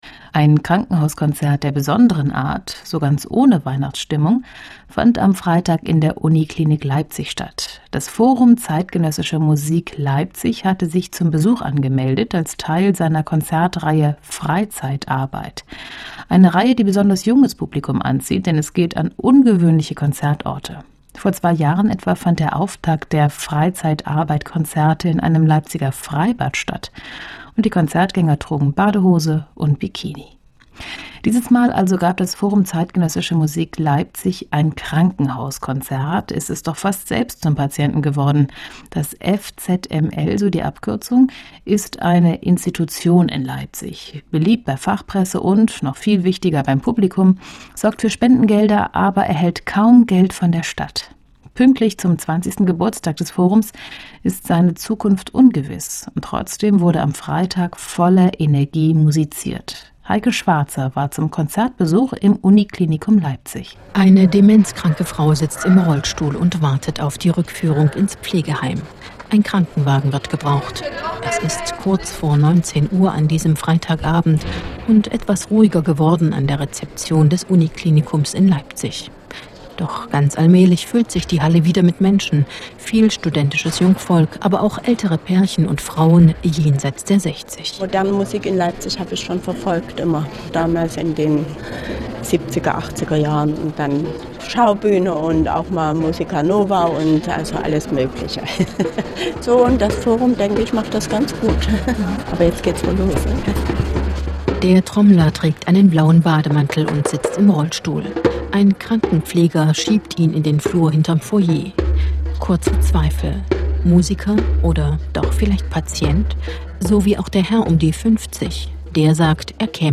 KRANKENHAUSKONZERT [Universitätsklinikum Leipzig, 26. November 2010]